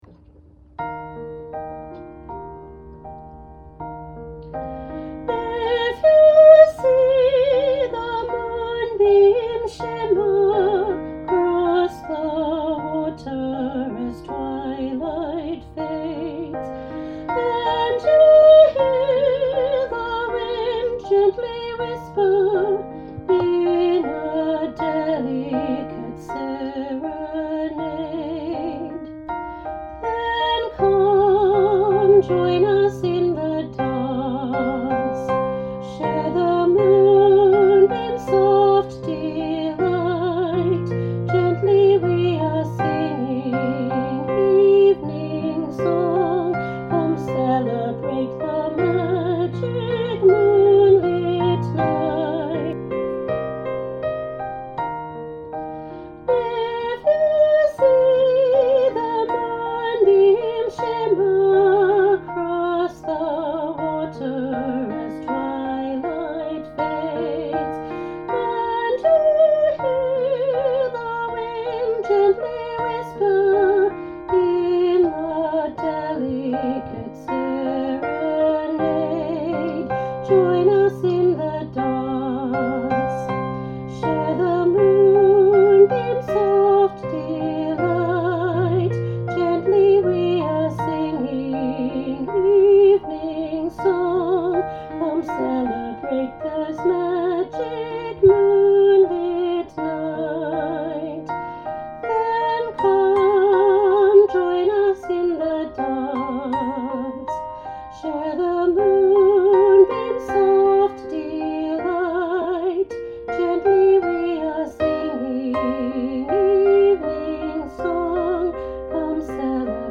Elementary Choir – Shining Moon, Part 2